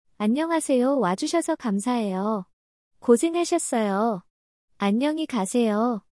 [AI 음성 생성] AWS Polly(폴리)를 이용한 TTS(Text To Speech, 음성 합성) 구현 (3) - Streamlit 프로젝트
만들어진 목소리는 아래와 같이 만들어져요.